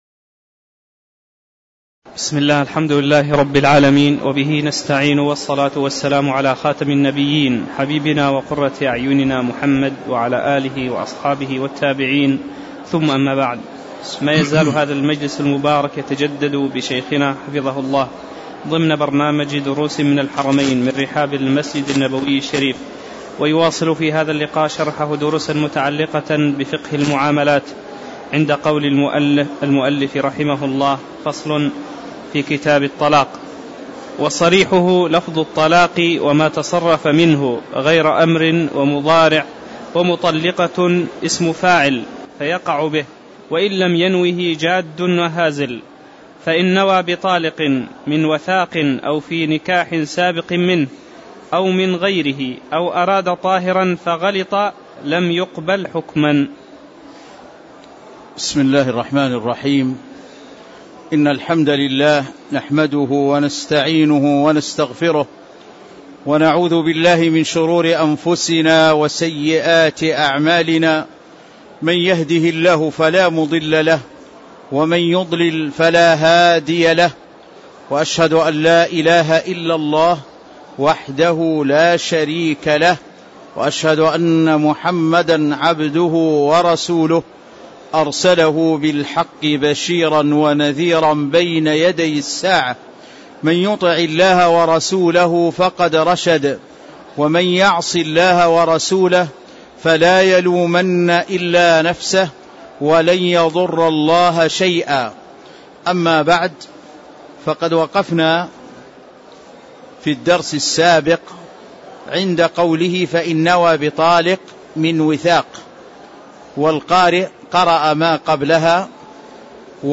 تاريخ النشر ٢٥ جمادى الآخرة ١٤٣٧ هـ المكان: المسجد النبوي الشيخ